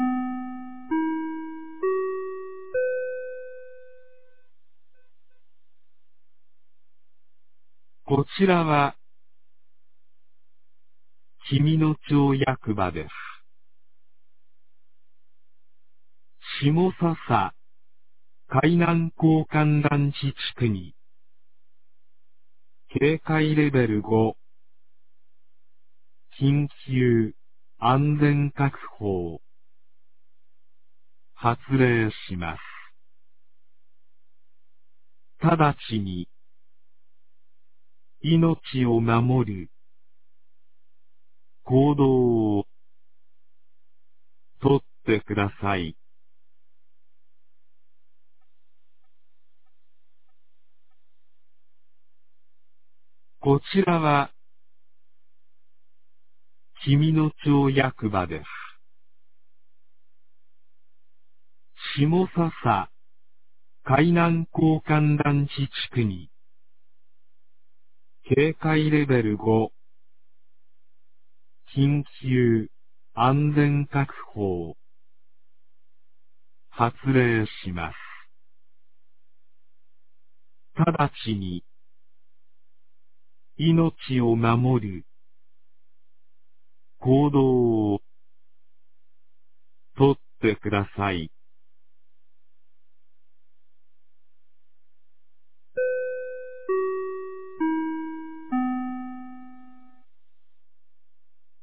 2023年06月02日 13時36分に、紀美野町より東野上地区へ放送がありました。